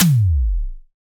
SI2 NOIZETOM.wav